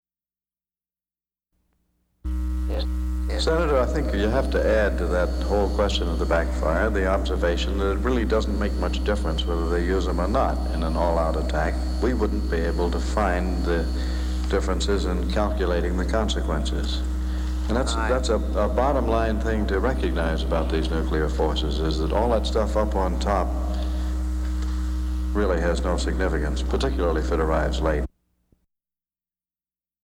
Admiral Noel Gayler, former director of the National Security Administration, testifies before Congress about the proposed second Strategic Arms Limitations Treaty. Gayler responds to a question by Senator Joseph Biden (D-DE) about the supposed lack of specific language regarding the ban of the Tupolev Tu-22M backfire bomber produced by the Soviet Union. Gayler says a treaty limitation on the production of the backfire bombers has no bearing on the passage of the treaty as a whole, and speculation of a nuclear attack should not hold sway in the debate of the treaty.
Broadcast on PBS, July 17, 1979.